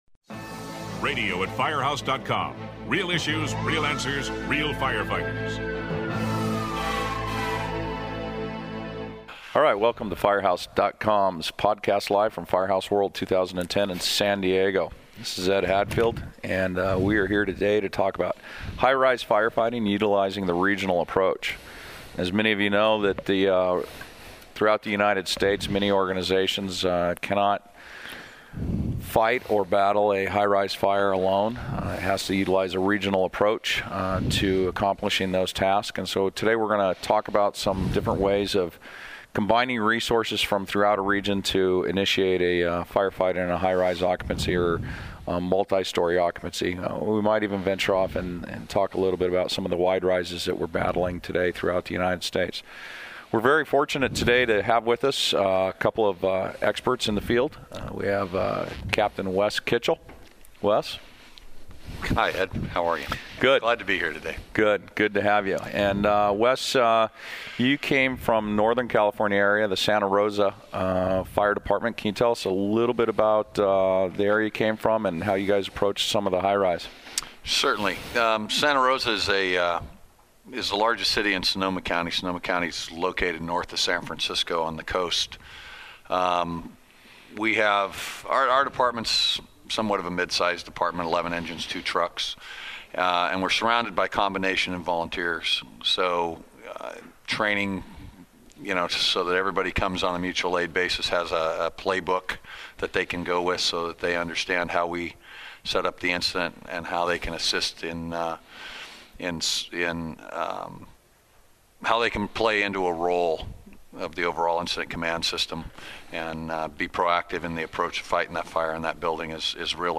Several veteran firefighters and officers from the west coast tackle the issue of high-rise firefighting and how their agencies use the approach from a regional response.